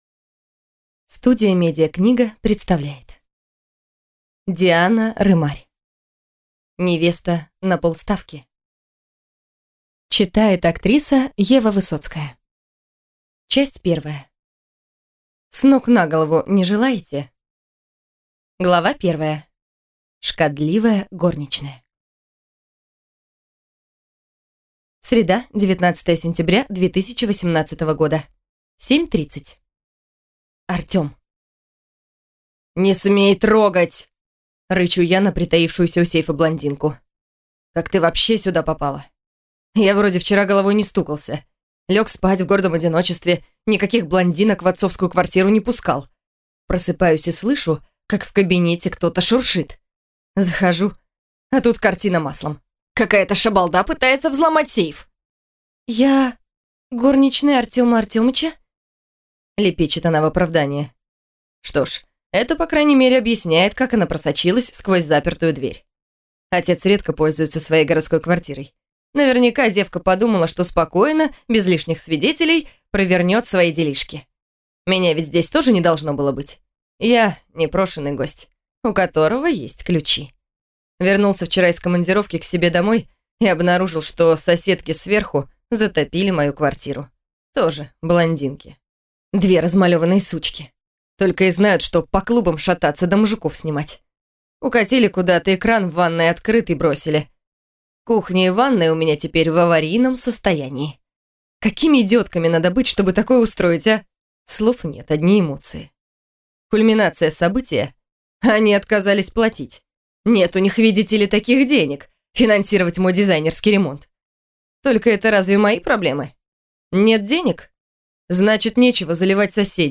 Аудиокнига Невеста на полставки | Библиотека аудиокниг